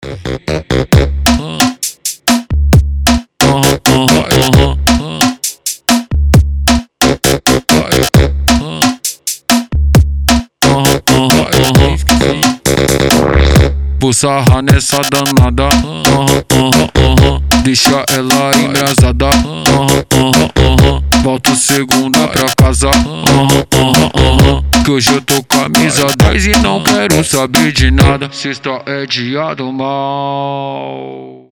• Качество: 320, Stereo
ритмичные
dancehall
Reggaeton